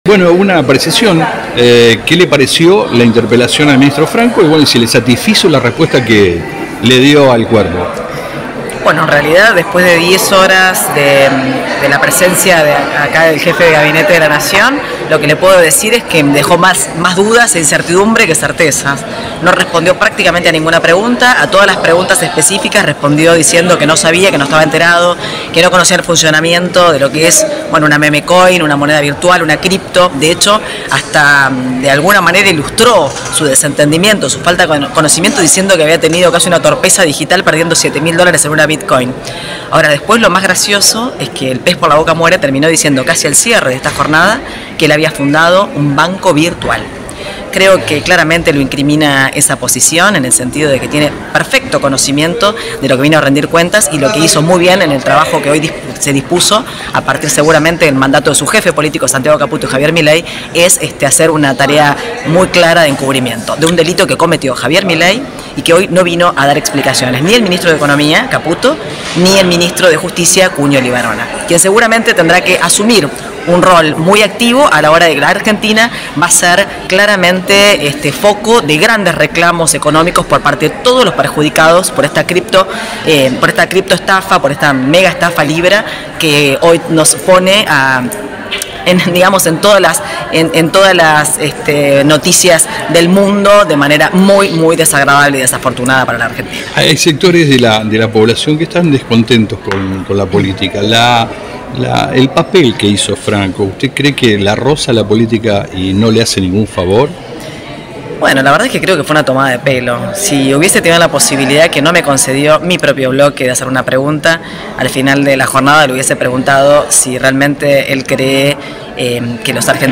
Dip. Agustina Propato